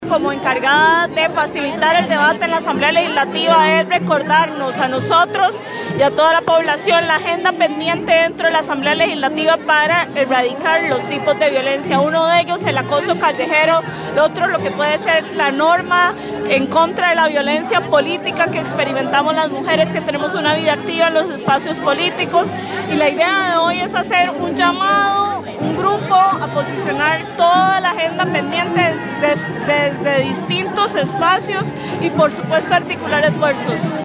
En la marcha se hicieron presentes otros jerarcas así como diputados y diputadas, entre ellas la presidente de la Asamblea Legislativa Carolina Hidalgo, quien aseguró que desde su puesto busca trabajar por la eliminación de la violencia contra las mujeres.